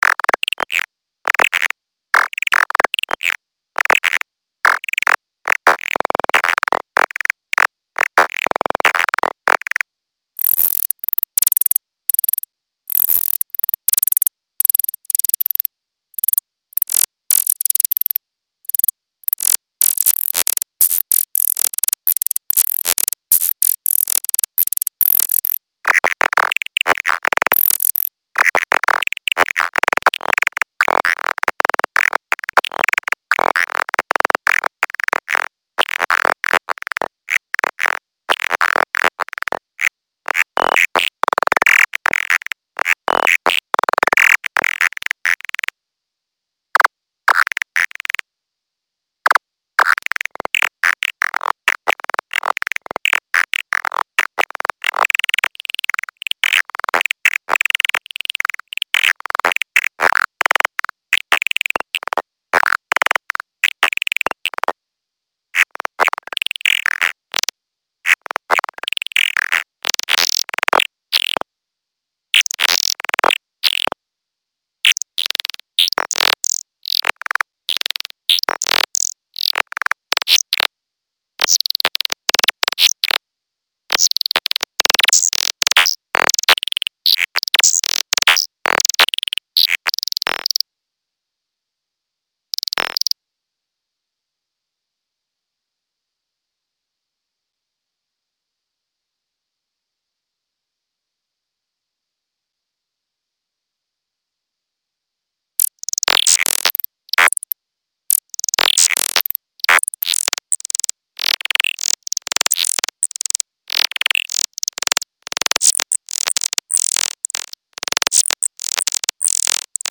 All birds sing in mono.